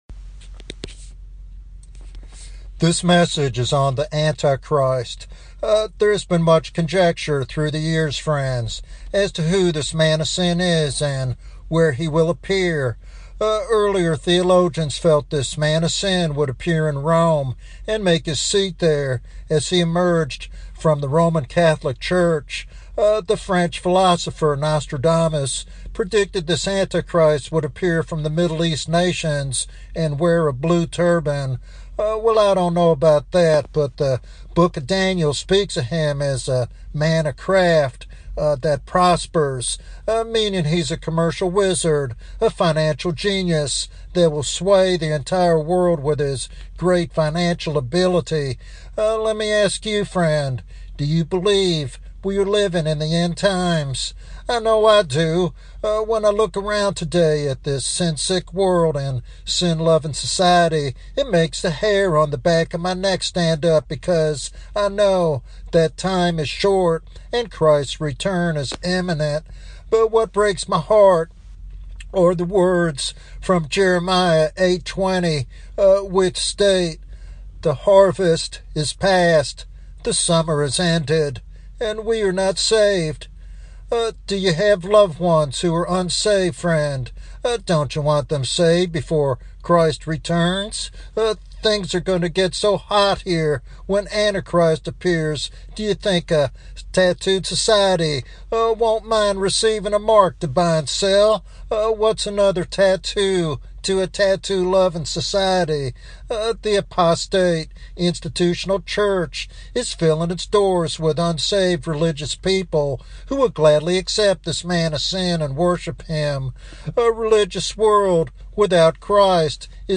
In this prophetic sermon